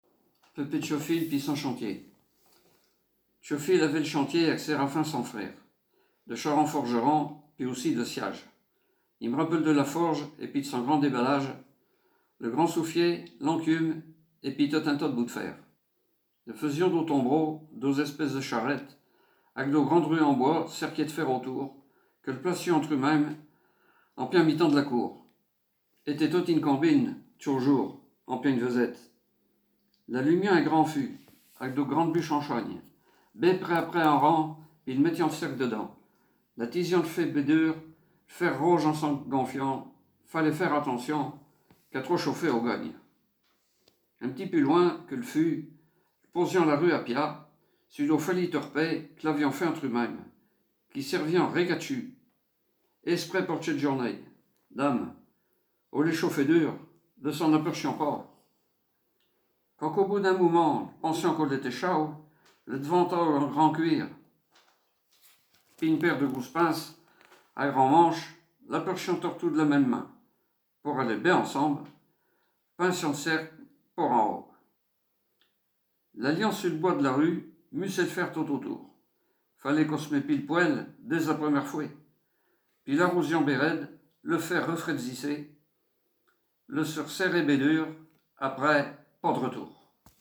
Genre poésie
Poésies en patois